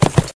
PixelPerfectionCE/assets/minecraft/sounds/mob/horse/gallop3.ogg at c12b93b9c6835a529eb8ad52c47c94bf740433b9
gallop3.ogg